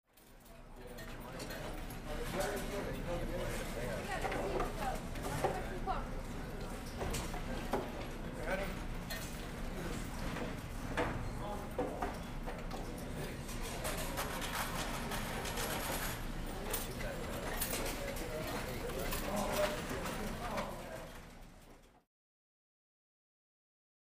Casino Ambience, W Light Walla And Voice Announcements On Pa.